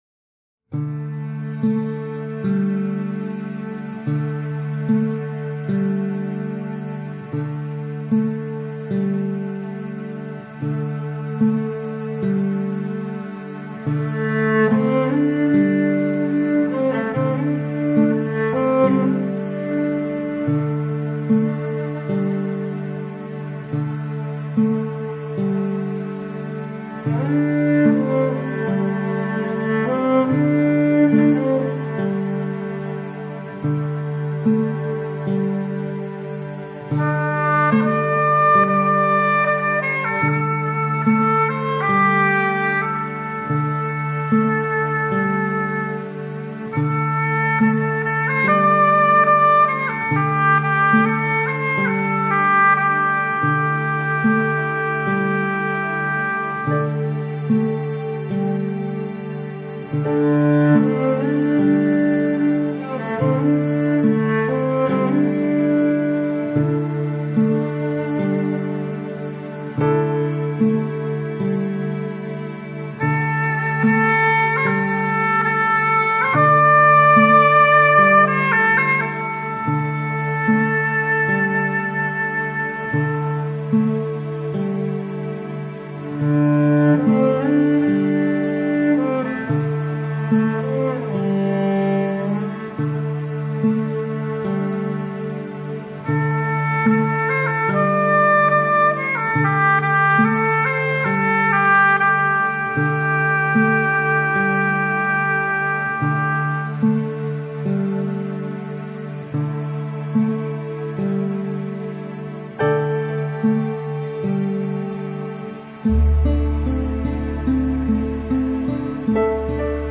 ★本專輯入圍2005年金曲獎「最佳跨界音樂專輯」獎★